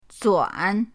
zuan3.mp3